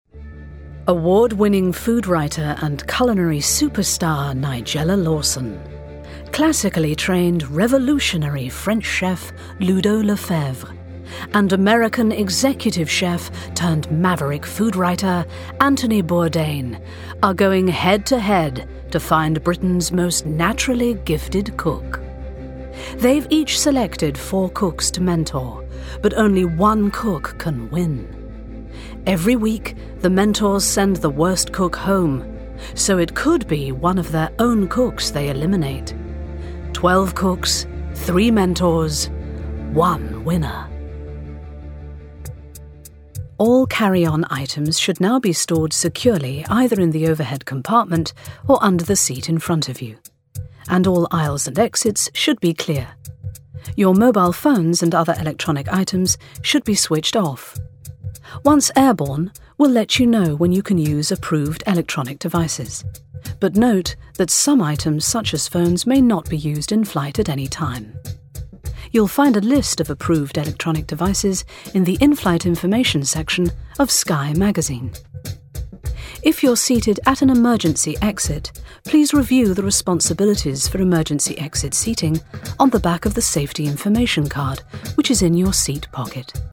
• Native Accent: RP
• Home Studio